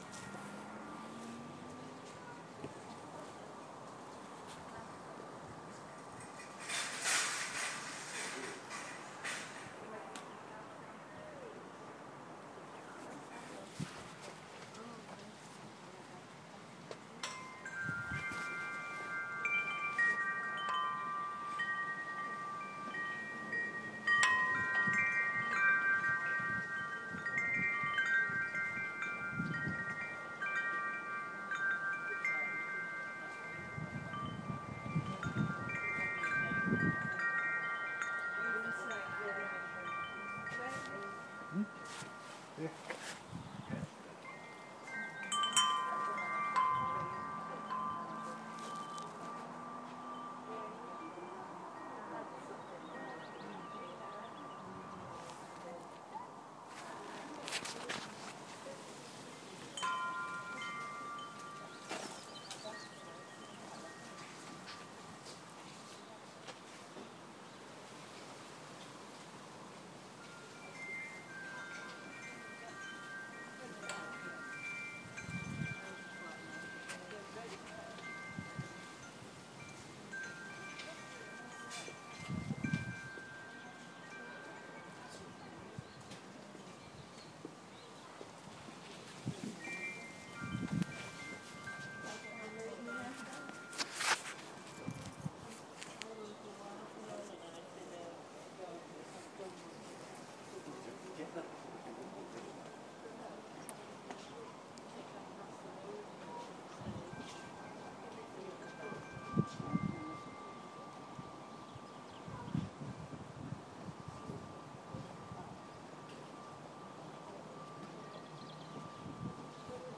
Wind Chime Gargrave